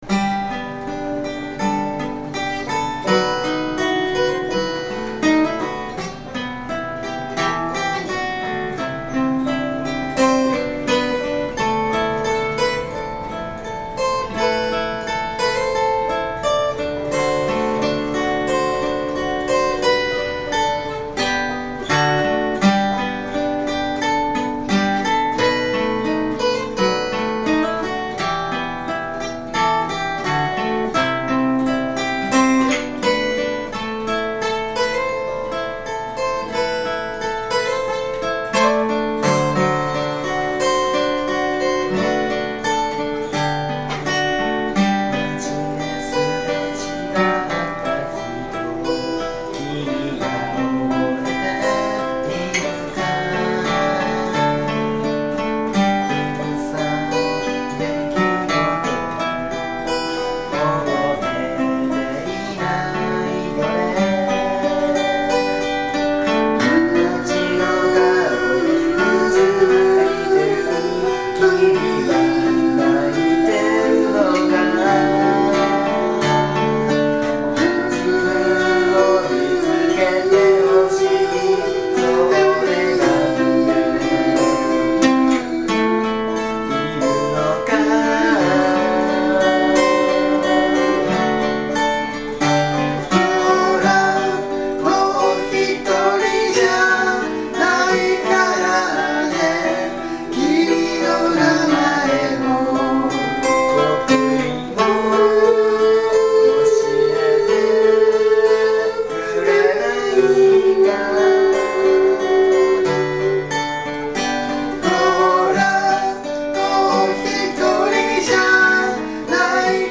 Bluegrass style Folk group
Key of G
企画名: Studio Live III
録音場所: 与野本町BIG ECHO
リードボーカル、ギター
コーラス、バンジョー